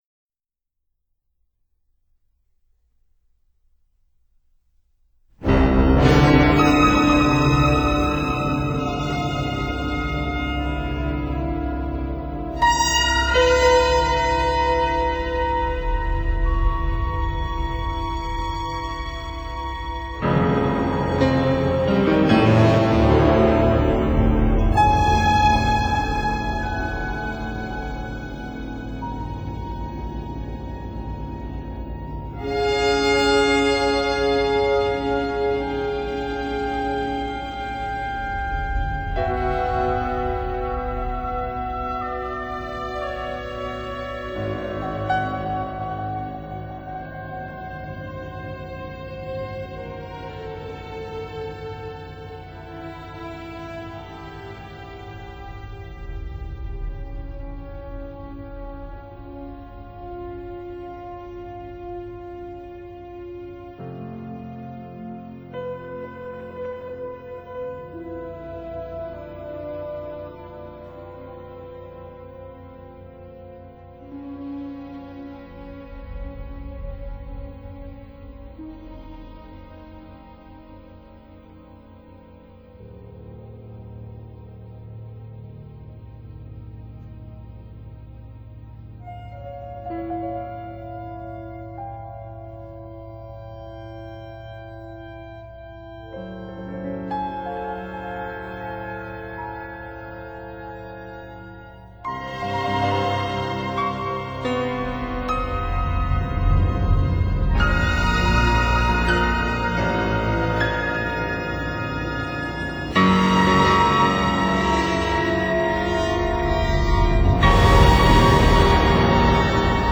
Symphony for Piano and Orchestra
Symphonic Poem for Piano and Orchestra
piano